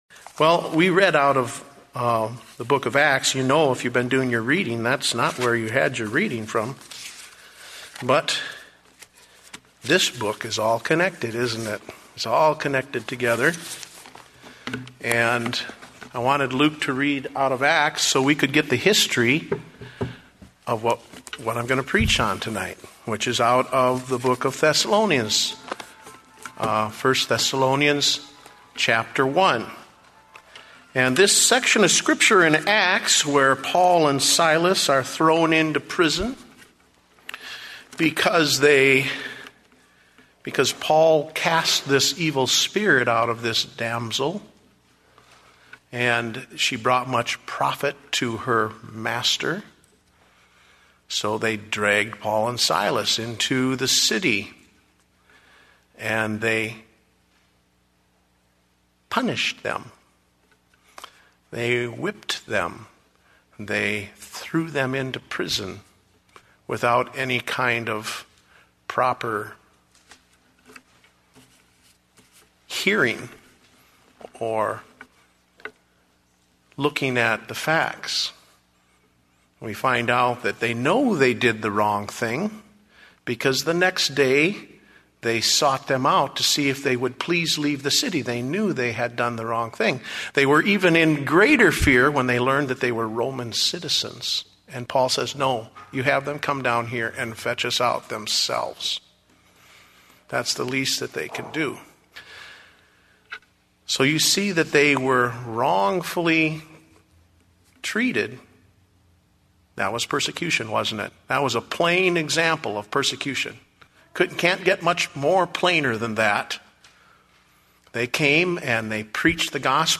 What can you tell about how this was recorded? Date: October 18, 2009 (Evening Service)